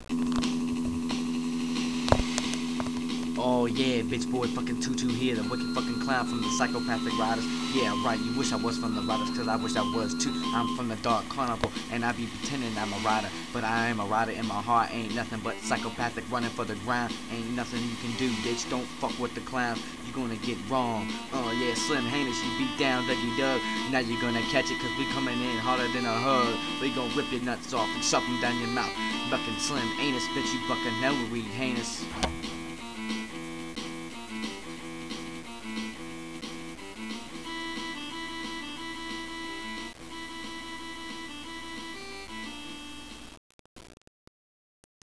This was really a freestyle I had done, so its not tha shit.